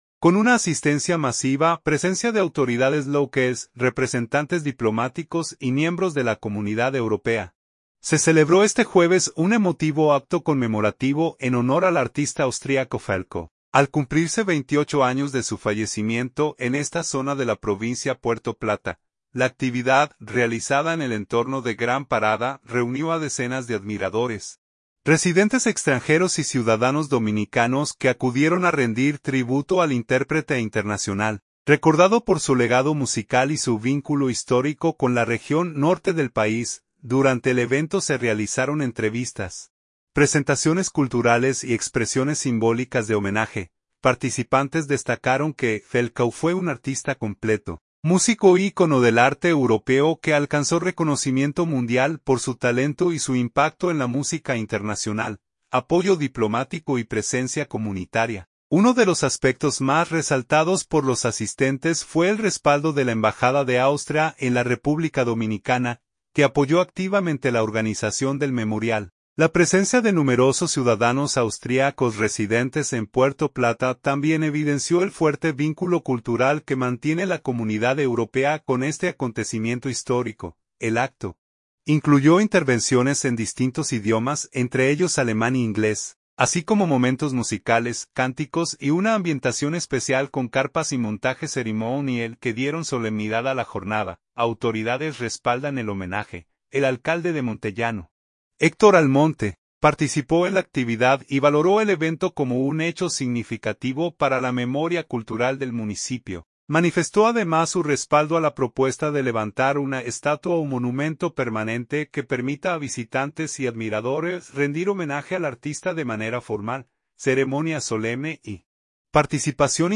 Durante el evento se realizaron entrevistas, presentaciones culturales y expresiones simbólicas de homenaje.
El acto incluyó intervenciones en distintos idiomas, entre ellos alemán e inglés, así como momentos musicales, cánticos y una ambientación especial con carpas y montaje ceremonial que dieron solemnidad a la jornada.